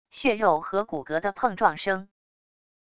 血肉和骨骼的碰撞声wav音频